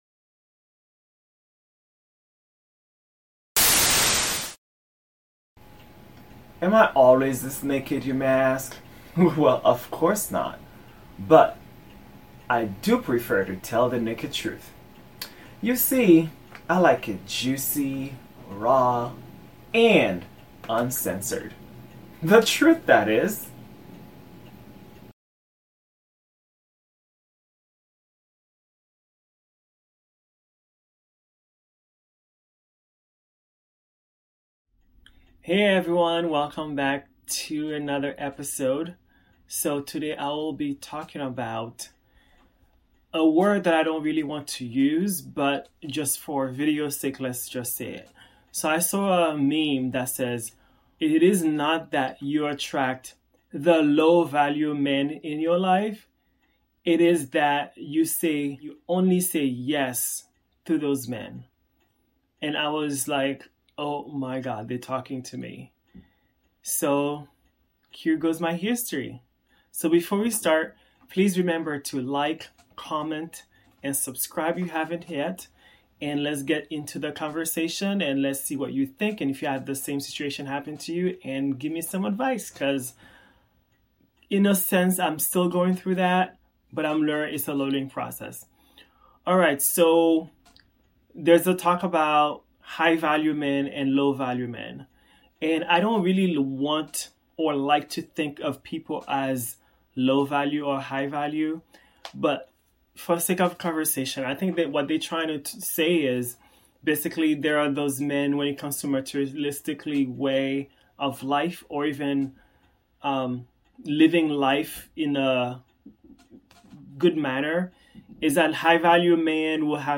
Solo.